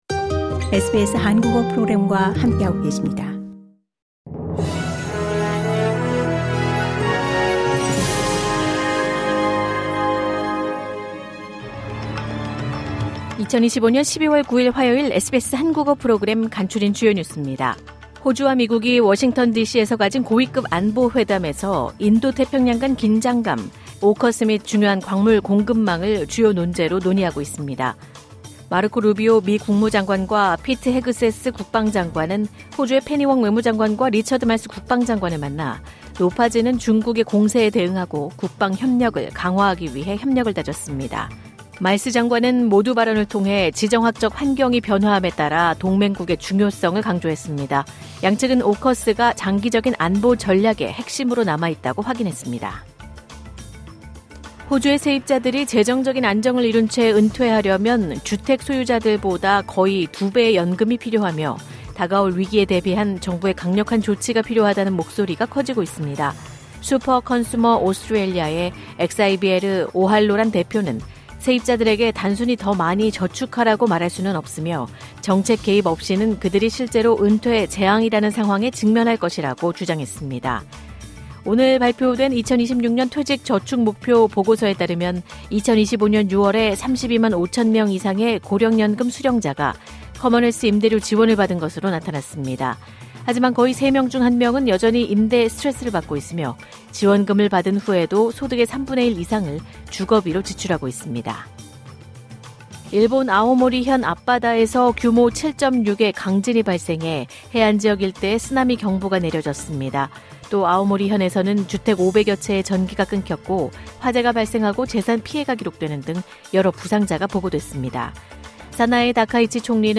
호주 뉴스 3분 브리핑: 2025년 12월 9일 화요일